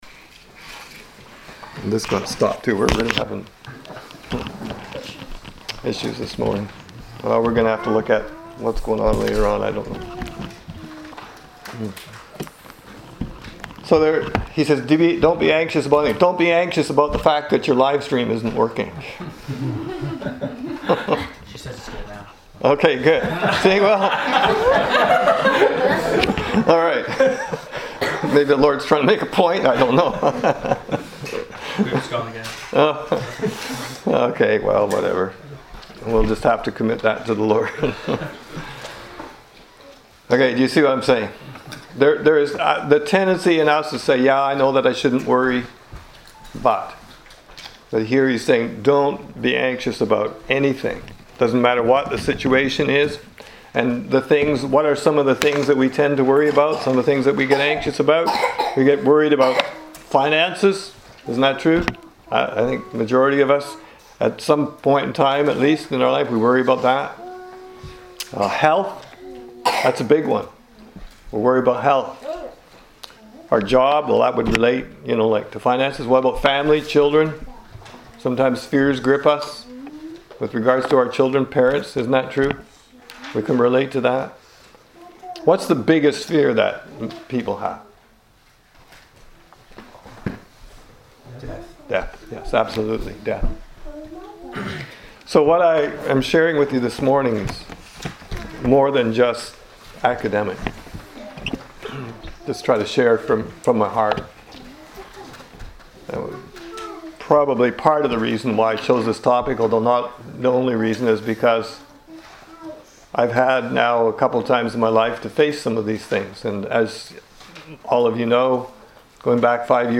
[This Recording was started a few minutes into the sermon]